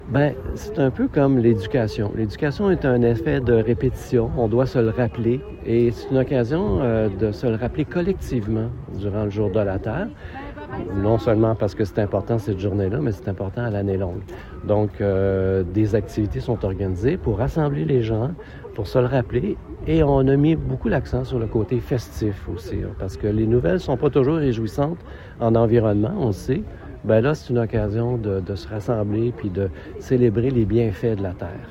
En entrevue avec le service des nouvelles de M105, à la question « pourquoi est-il toujours important de célébrer le Jour de la Terre en 2026 »